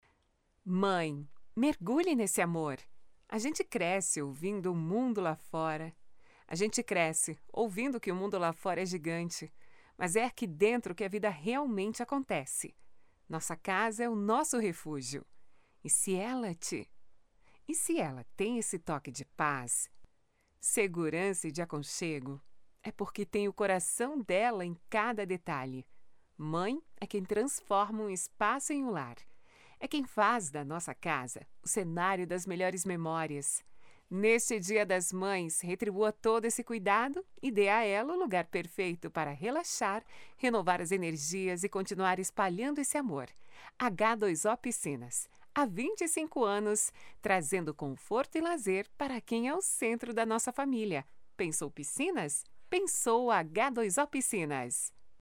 MÃE PROMO PISCINAS: